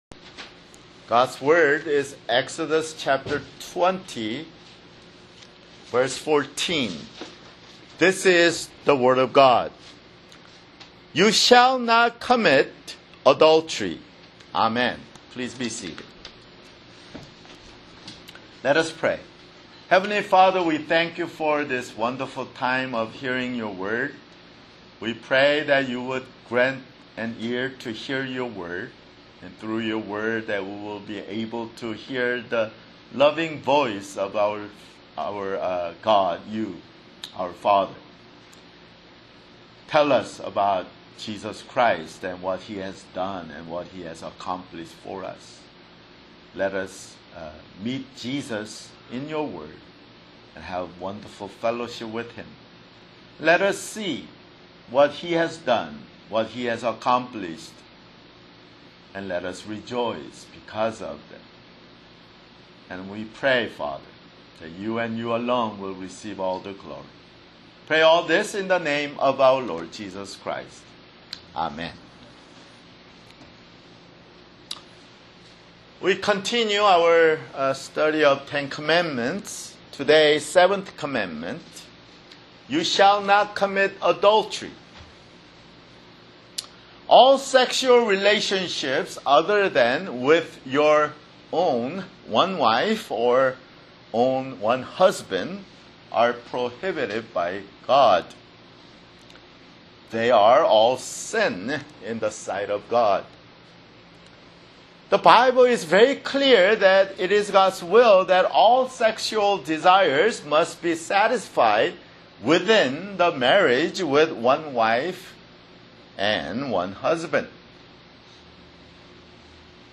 [Sermon] Exodus (54)